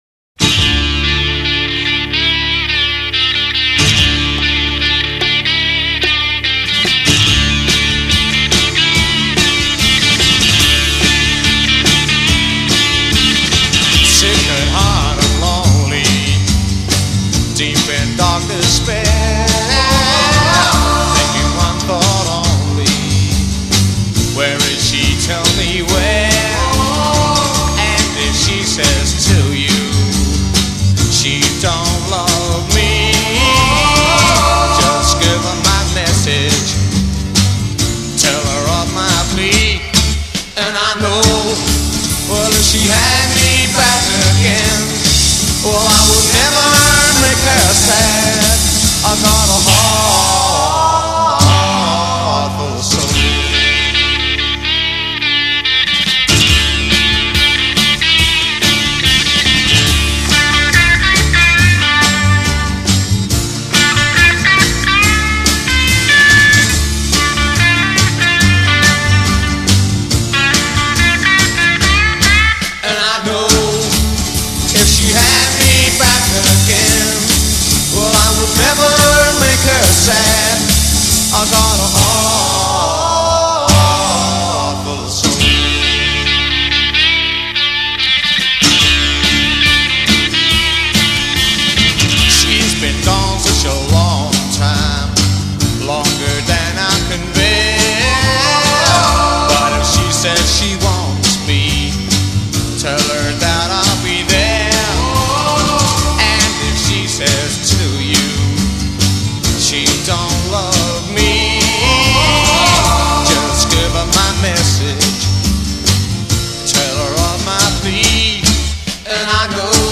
vocals
drums
guitar solos
Recorded at Advision Studios, London, 20 April 1965.
Intro 8 Guitar (Fender telecaster with booster)
Verse 16 Solo voice
responded to by second voice with heavy reverberation a
Chorus 7 Beat subdivision doubles b
Verse* 8 Guitar solo built around verse melody